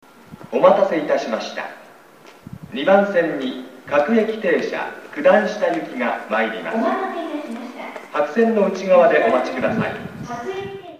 駅放送